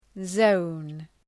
Προφορά
{zəʋn}